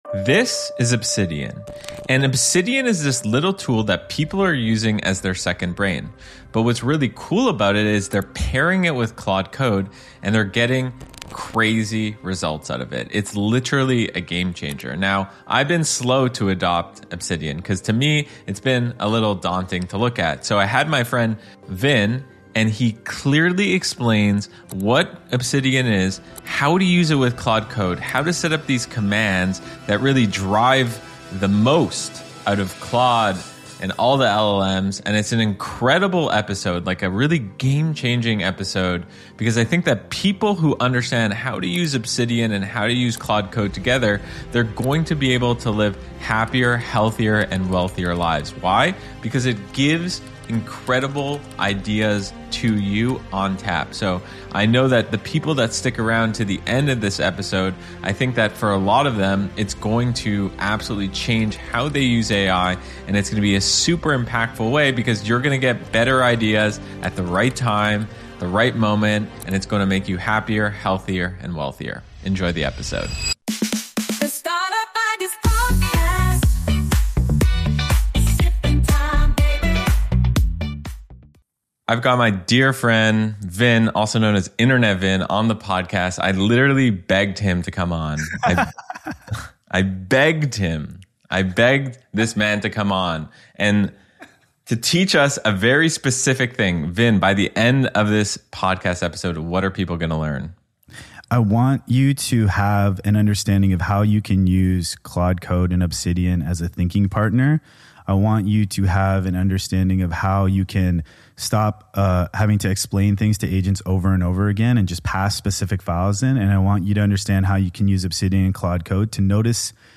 Live Demo